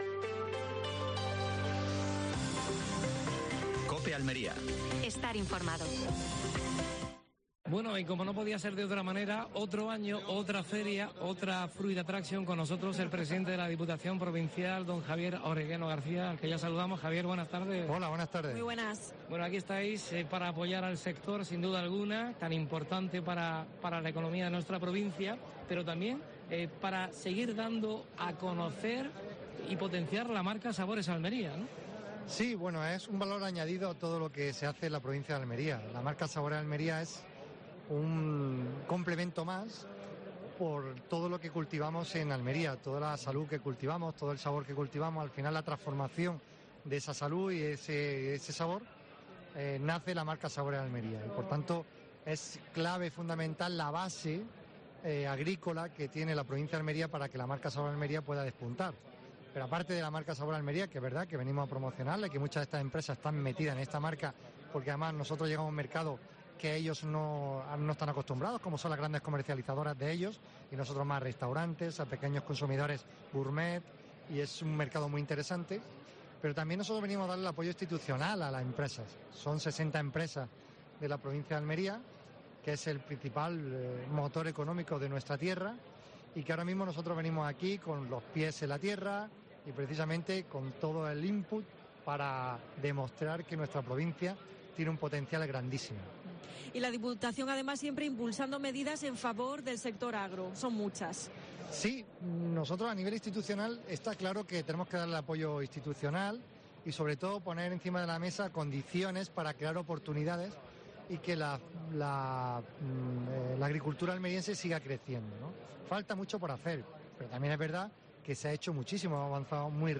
AUDIO: Programa especial desde Fruit Attraction (Madrid). Entrevista a Javier Aureliano García (presidente de la Diputación Provincial de Almería).